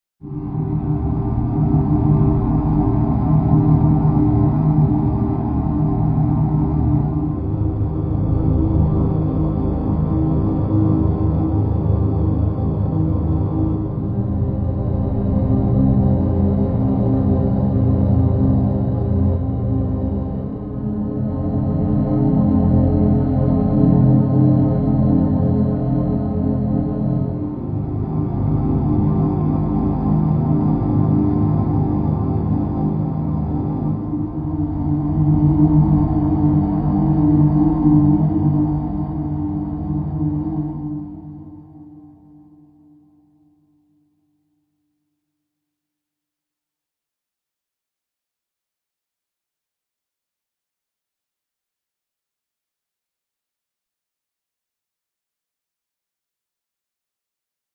Science fiction electronic sound fx
Low electronic science fiction pad.
Tags: pad
32kbps-Triond-low-pad.mp3